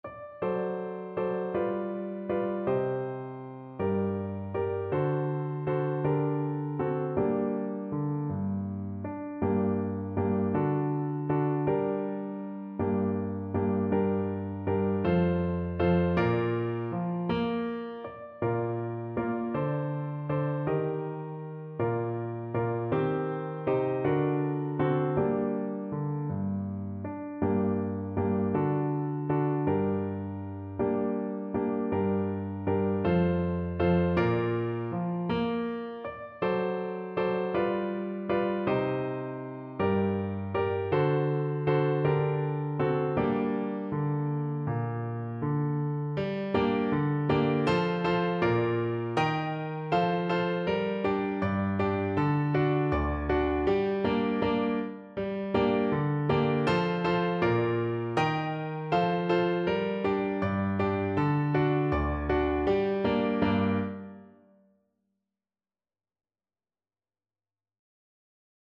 6/8 (View more 6/8 Music)
Andante